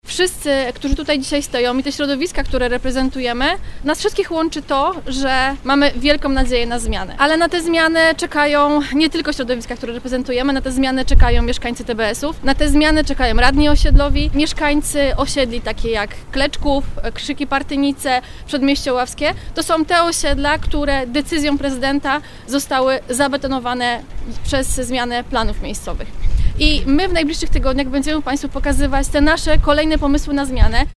– .Nowoczesna od samego początku jest częścią Koalicji Obywatelskiej i to naturalne, że dołączamy do tego grona. – dodaje poseł na Sejm, Jolanta Niezgodzka.